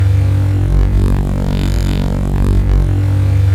sci-fi_deep_electric_hum_loop_01.wav